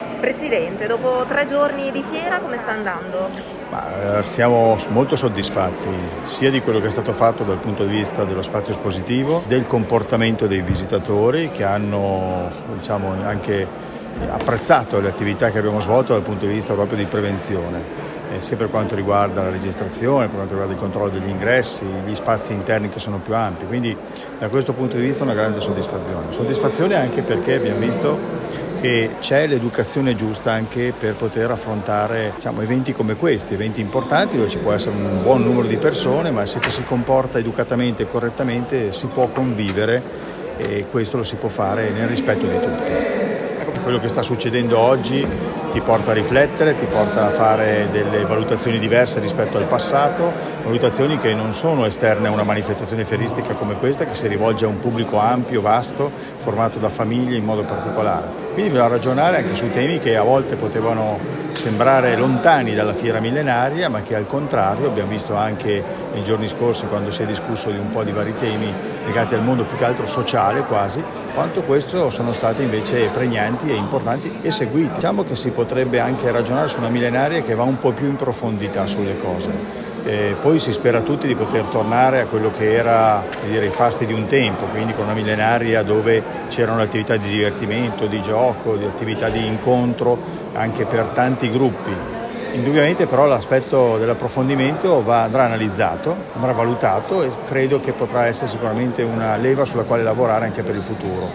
Eccolo al microfono